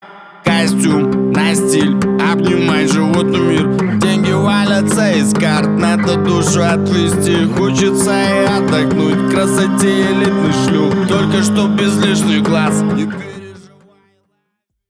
• Качество: 320, Stereo
мощные басы
блатные
гангстерские
Первый куплет крутого трека белорусского рэпера.